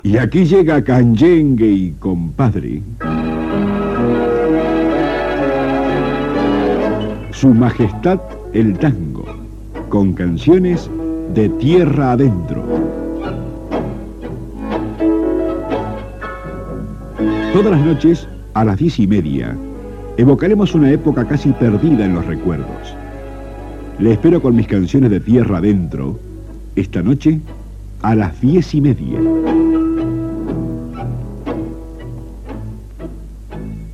Promoció del programa musical de tangos.